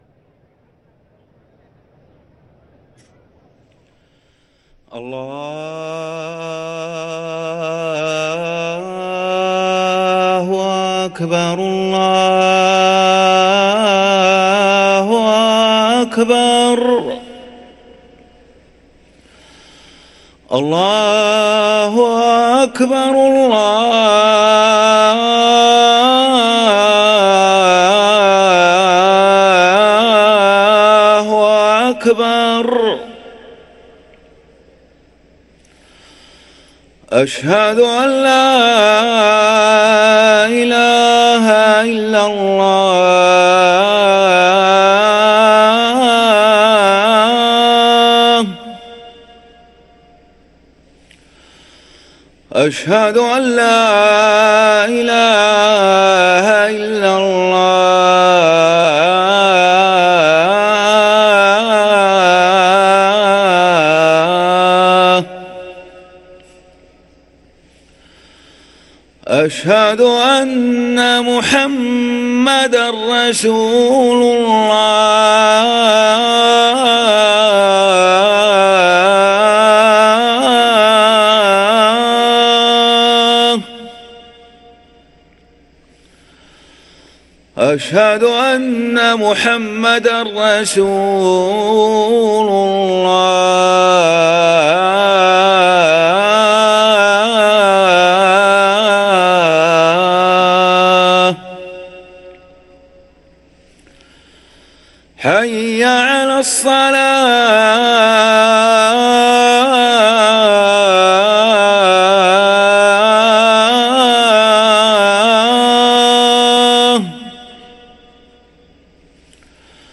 أذان الفجر للمؤذن ماجد العباس الأربعاء 13 شوال 1444هـ > ١٤٤٤ 🕋 > ركن الأذان 🕋 > المزيد - تلاوات الحرمين